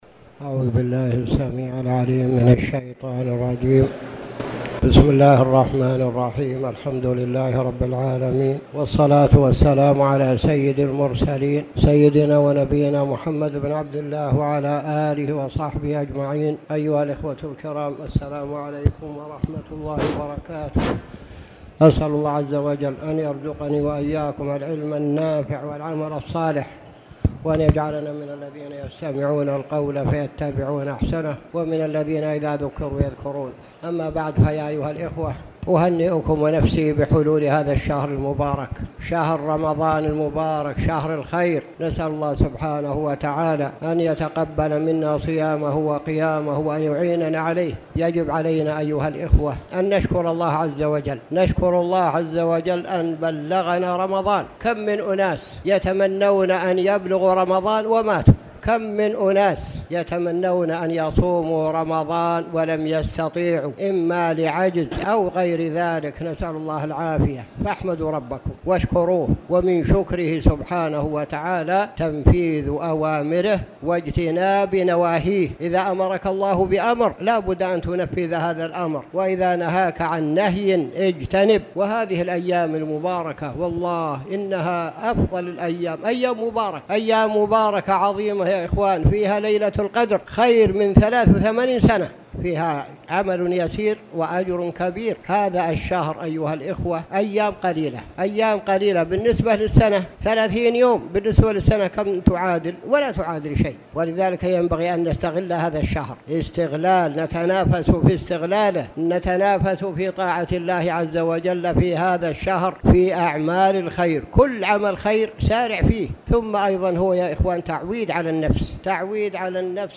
تاريخ النشر ١ رمضان ١٤٣٨ هـ المكان: المسجد الحرام الشيخ